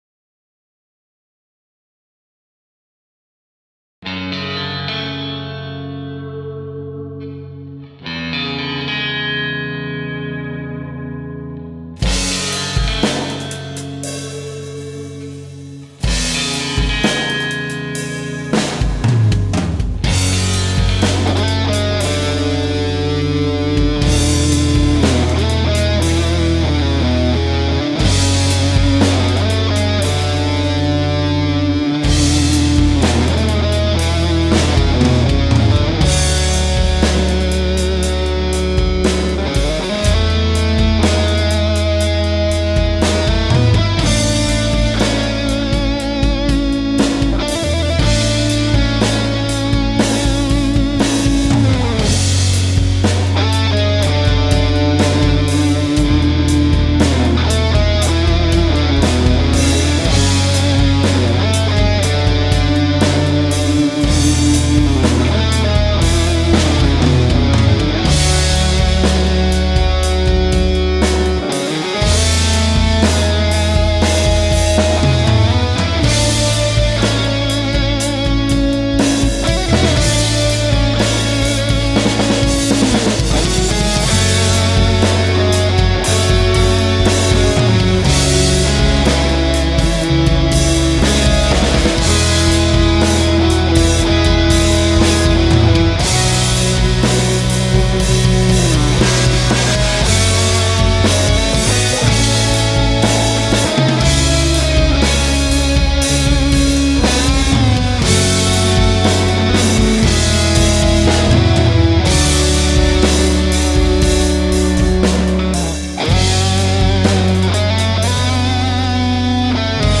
I just did a quick mix here with his real drum track.
Like it with real drums even more!
The drums sound great.
PassionDrums.mp3